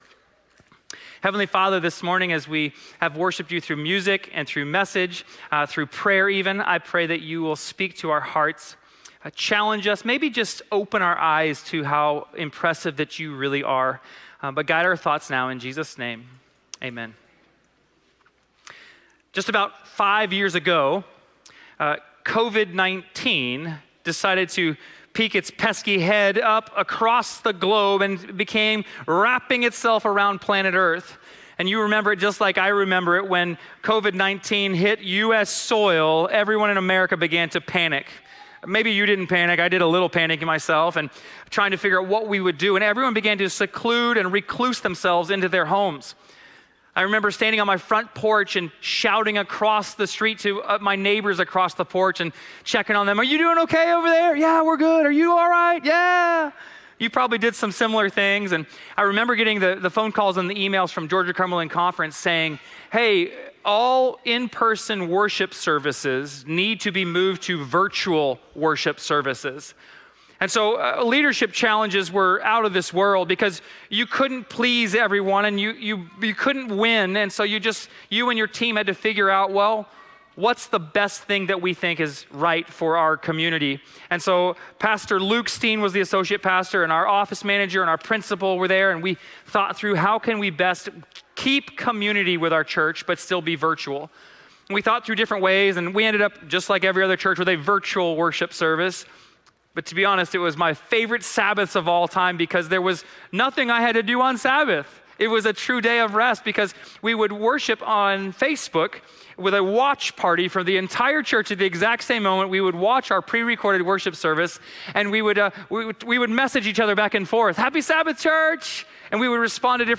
Forest Lake Church Sermon Podcast